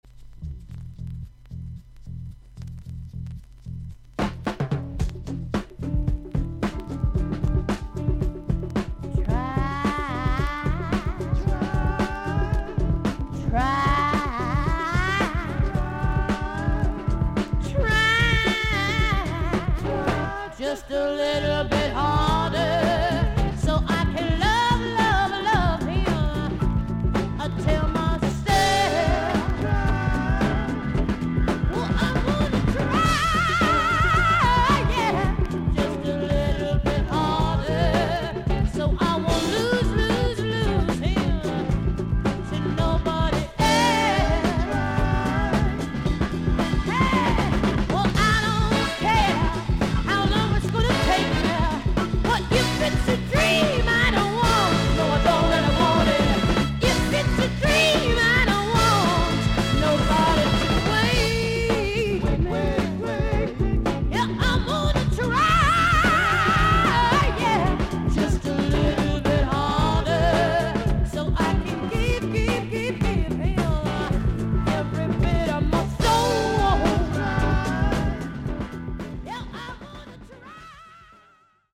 女性ロック・シンガー。
VG++〜VG+ 少々軽いパチノイズの箇所あり。クリアな音です。